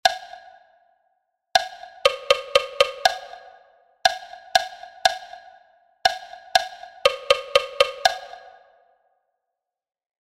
motivo_ritmico_3.mp3